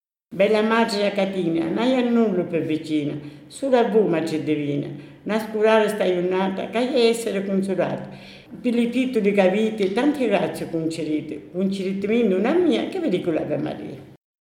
PREGHIERE E CANTI RELIGIOSI